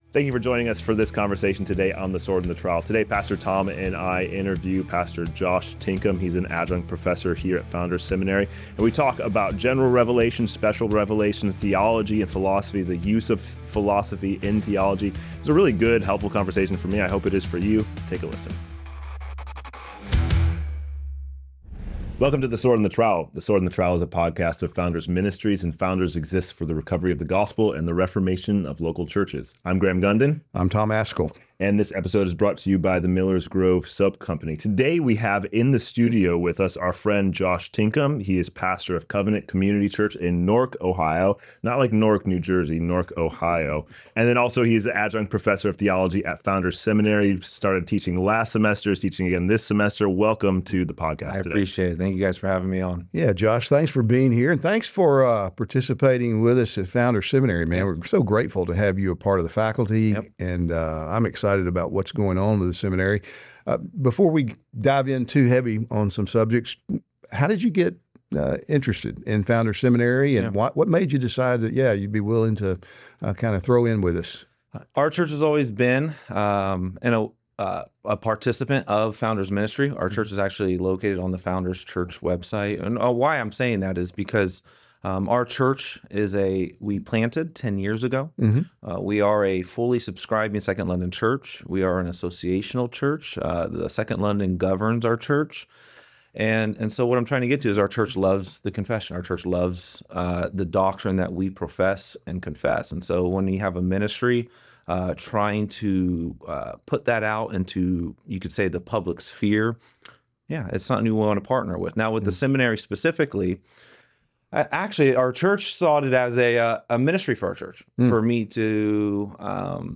TS&TT: Theology and Philosophy: A Discussion